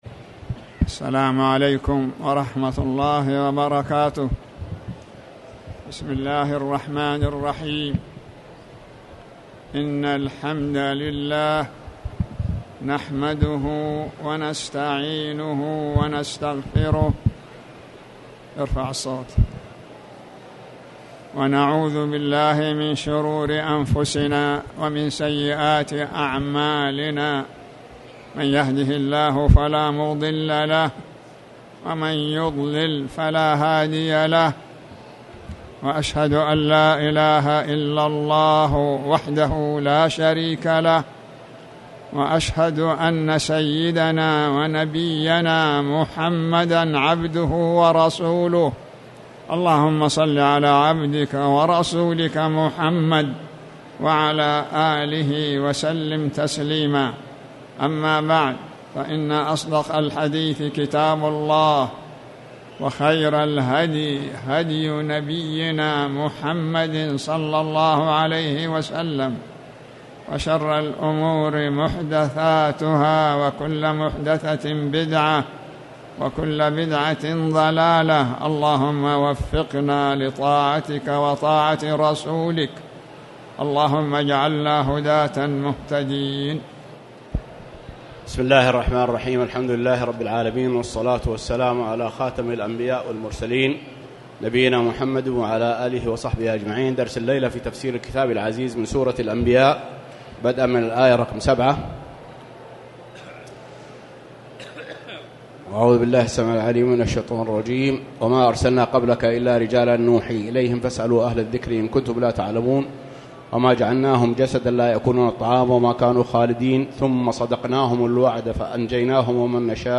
تاريخ النشر ١٨ شوال ١٤٣٨ هـ المكان: المسجد الحرام الشيخ